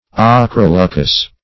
Search Result for " ochroleucous" : The Collaborative International Dictionary of English v.0.48: Ochroleucous \Och`ro*leu"cous\, a. [Gr.